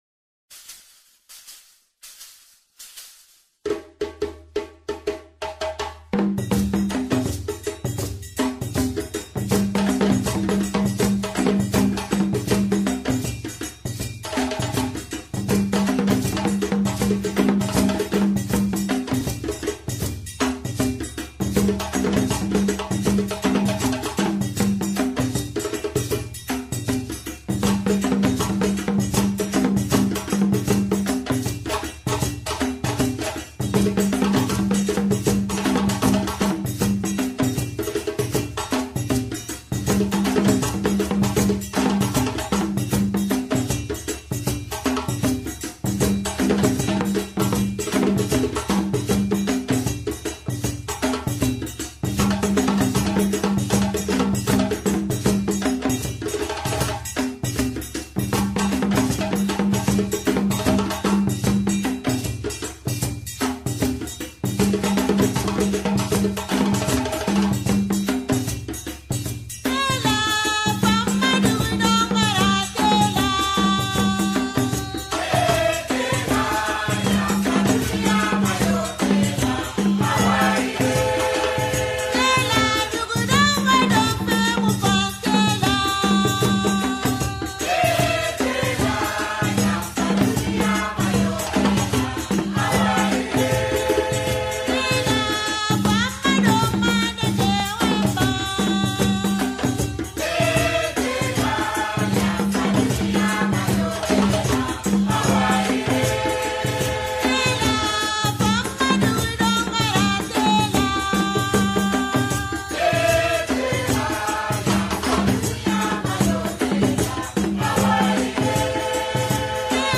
Percusión africana
Audio de seis minutos de percusión africana.